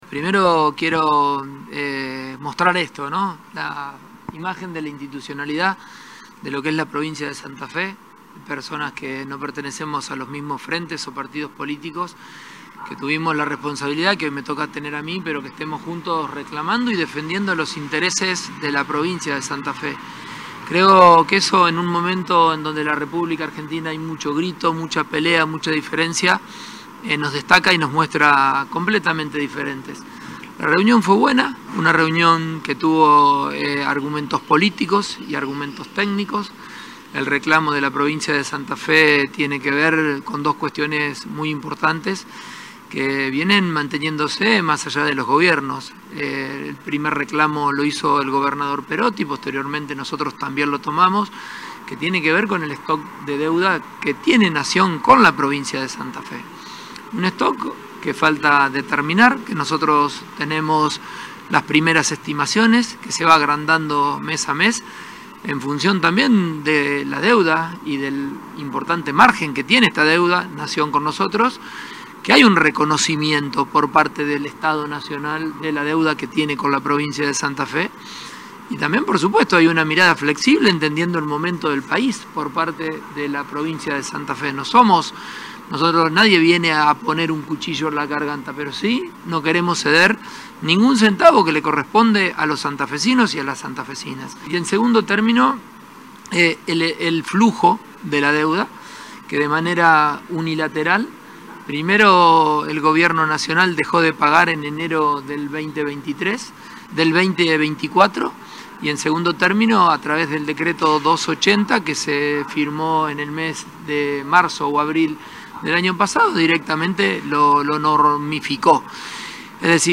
En otro tramo de su declaración, el gobernador remarcó que “Santa Fe hoy tiene equilibrio fiscal, porque todas las gestiones que nos antecedieron han sido responsables”.